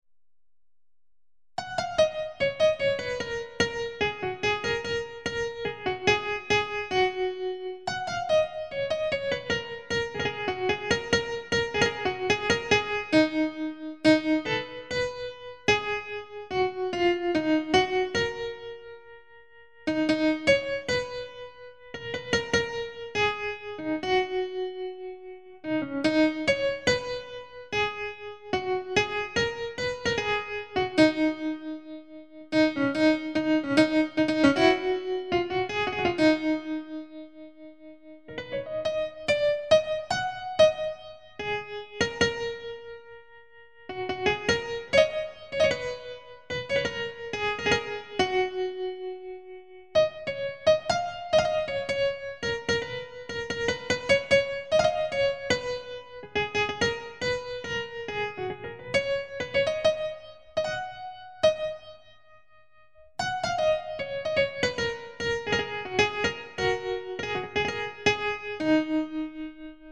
ככה אמורה להישמע הקלטה פנימית של אורגן